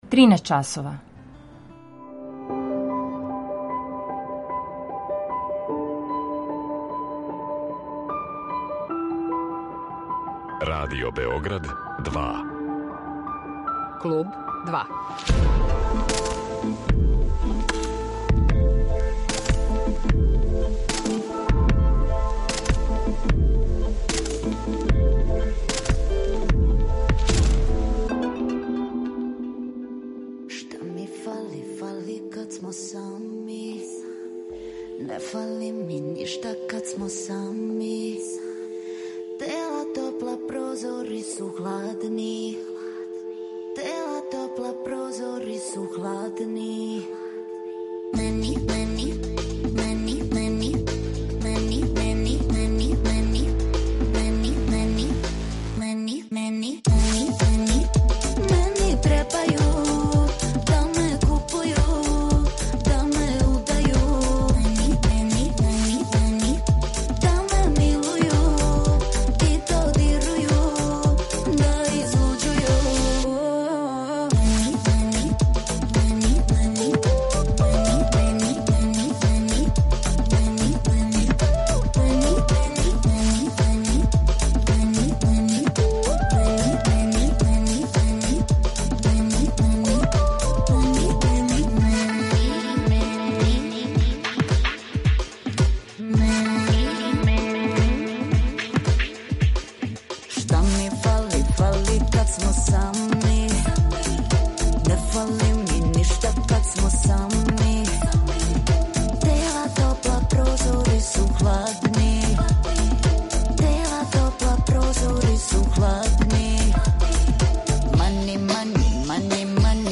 Наравно, слушаћемо и Бојанине песме.